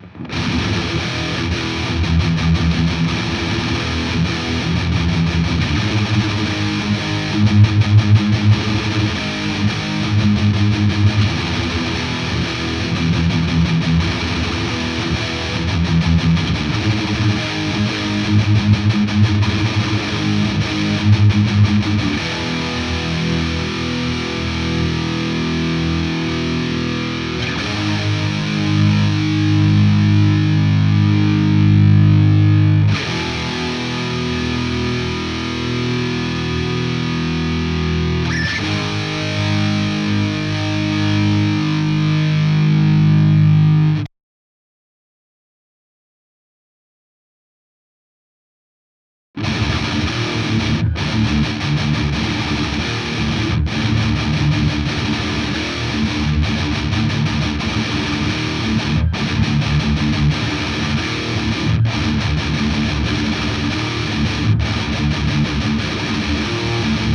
voilà de nouveau reamp où j'ai mis un son de base plus dans mes goûts.
101B : canal Red - Red to Normal
Ces sons sont bruts, j'ai juste nettoyé le trou au milieu.